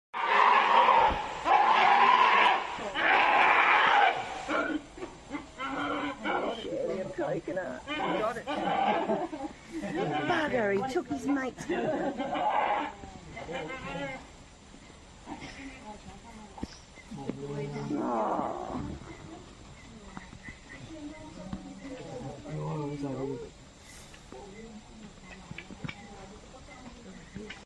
Beruang madu mengeluarkan pelbagai jenis bunyi bergantung kepada keadaan. Ini contoh bunyi yang didengar apabila beruang madu berebut makanan. Sekali dengar, seperti bunyi anjing yang bergaduh.